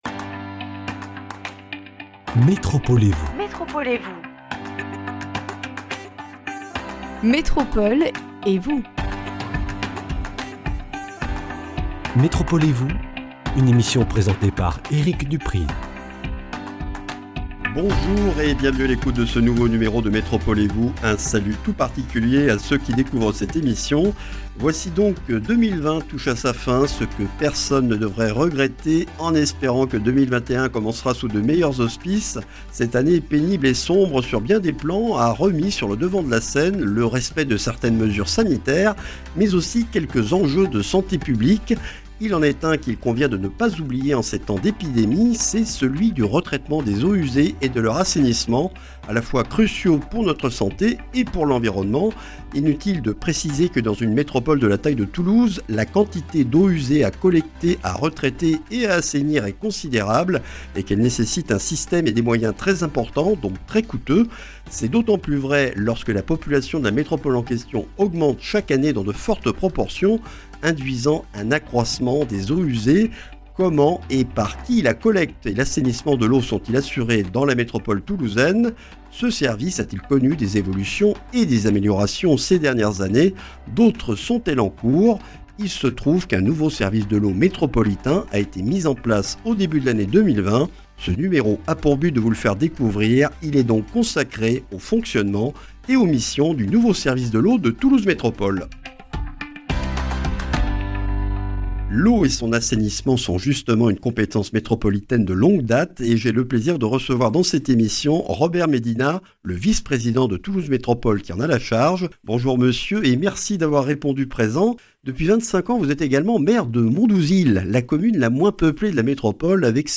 Robert Médina, maire de Mondouzil et vice-président de Toulouse Métropole chargé de l’Eau et de l’Assainissement, est l’invité de ce numéro consacré au fonctionnement du cycle de l’eau dans la métropole toulousaine. Un nouveau service de l’eau de Toulouse Métropole est en place depuis le 1er janvier 2020, nous en découvrons l’organisation, les missions et les innovations récentes.